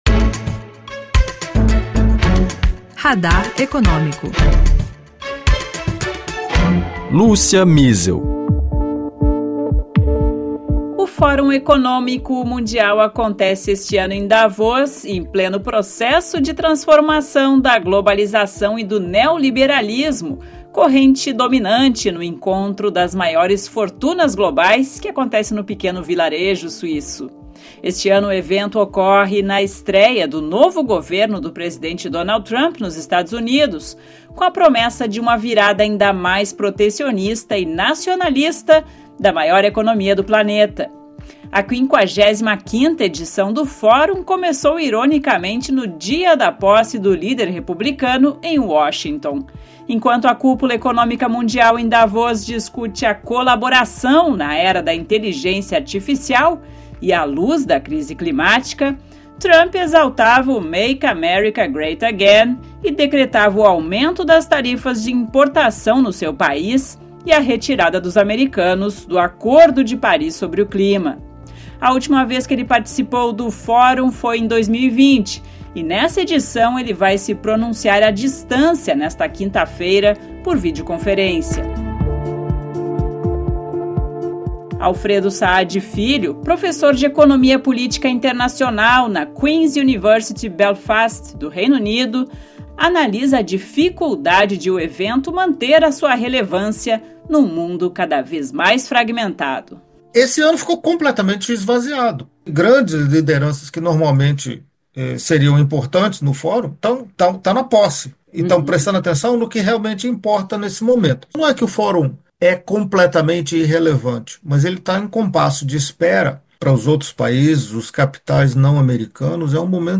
Entrevistas com economistas, analistas de mercado, investidores e políticos, para explicar e comentar questões econômicas internacionais. O papel do Brasil e dos países emergentes na economia mundial.